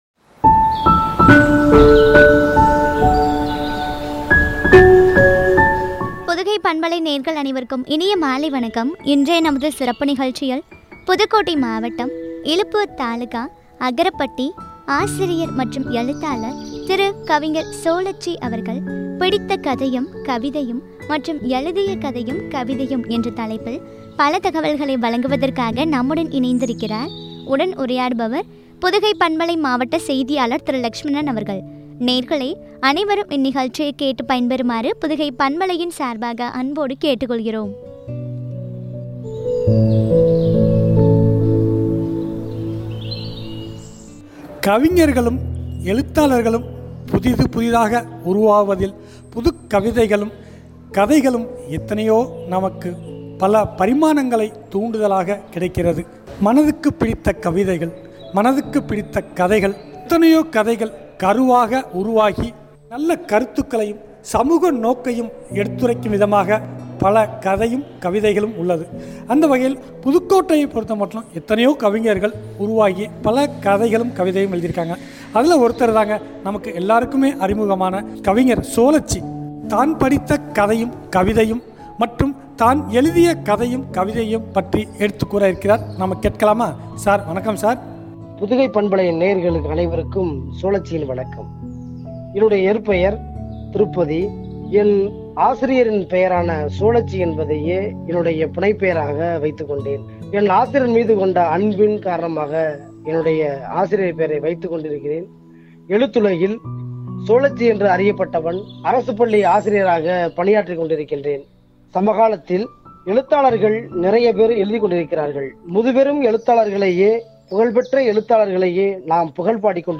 கவிதையும் என்ற தலைப்பில் வழங்கும் உரையாடல்.